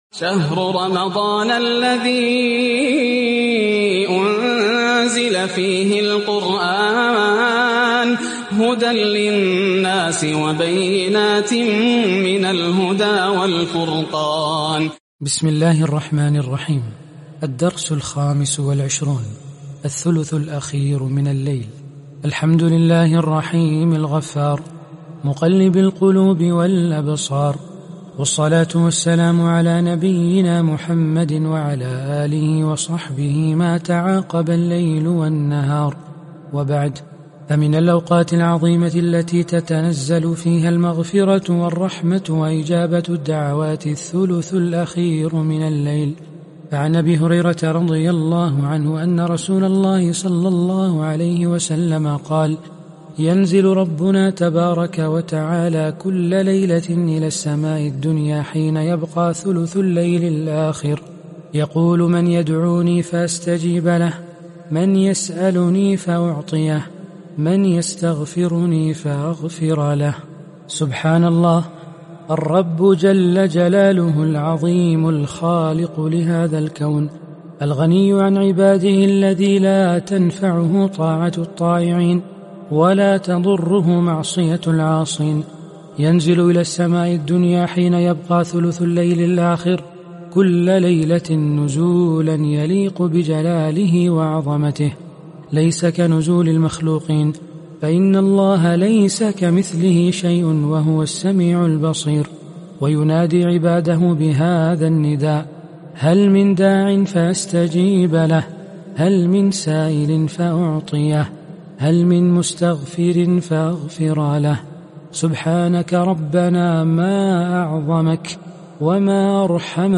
عنوان المادة (26) القراءة الصوتية لكتاب عقود الجمان - (الدرس 25 الثلث الأخير من الليل)